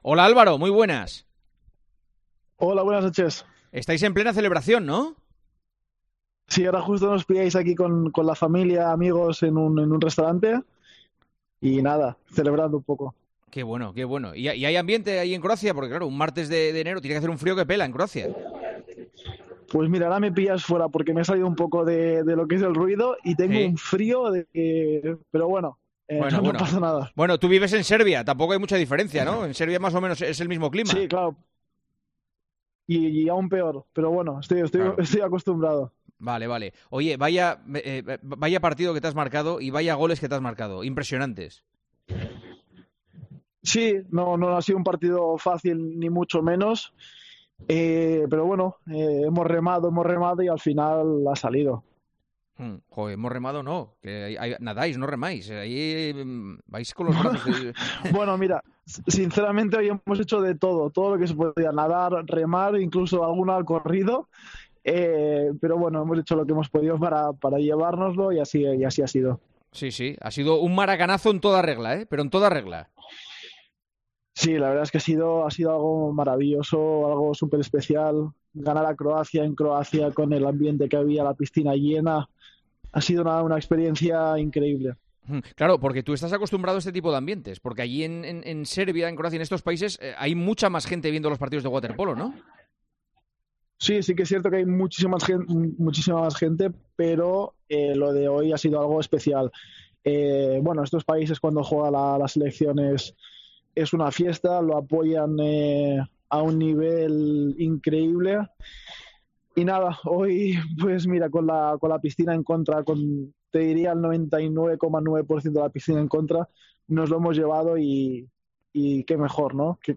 Escucha la distendida charla entre Juanma Castaño y Álvaro Granados, estrella de la selección española de waterpolo, campeona de Europa por primera vez.